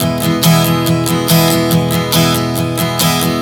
Strum 140 E 01.wav